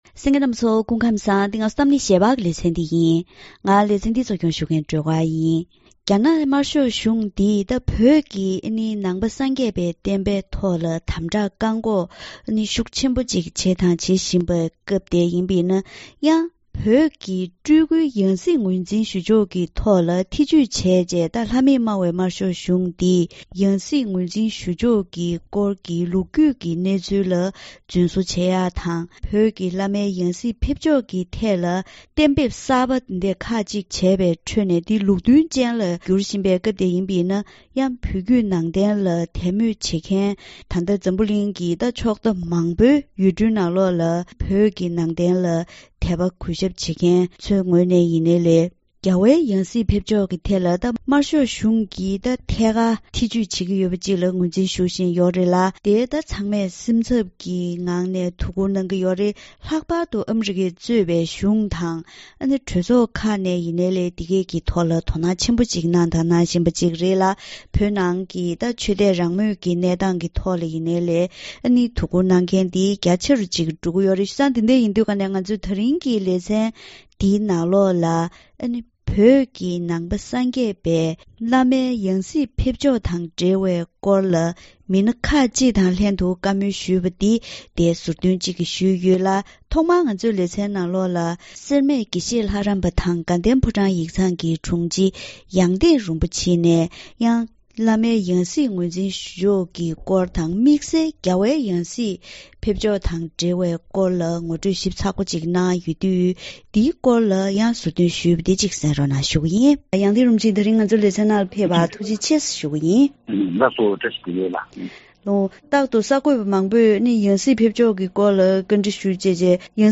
ད་རིང་གི་གཏམ་གླེང་ཞལ་པར་ལེ་ཚན་ནང་རྒྱ་ནག་དམར་ཤོག་གཞུང་གིས་བོད་ཀྱི་ནང་བསྟན་ལ་དམ་བསྒྲགས་བཀག་འགོག་དང་། ཕྱོགས་གཞན་ཞིག་ནས་ལྷ་མེད་སྨྲ་བའི་གཞུང་གིས་བོད་ཀྱི་སྤུལ་སྐུའི་ཡང་སྲིད་ངོས་འཛིན་ཞུ་ཕྱོགས་ཐད་ཆོས་ཕྱོགས་ཀྱི་ལམ་སྲོལ་ལ་བརྩི་བཀུར་མེད་པར་ཐེ་ཇུས་བྱེད་ཀྱི་ཡོད་པས། འབྲེལ་ཡོད་སྐོར་ལ་མི་སྣ་ཁག་ཅིག་ལྷན་བཀའ་མོལ་ཞུས་པ་ཞིག་གསན་རོགས་གནང་།